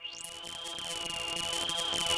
Machine09.wav